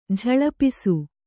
♪ jhaḷapisu